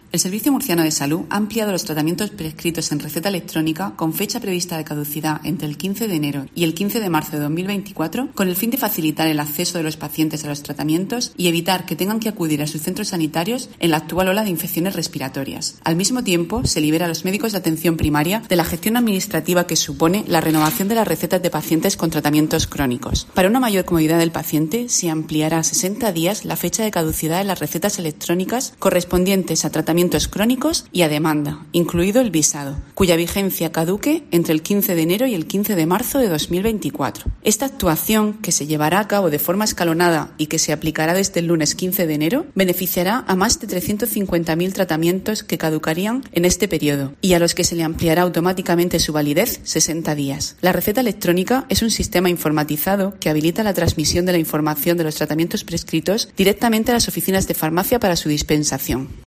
Isabel Ayala, gerente del Servicio Murciano de Salud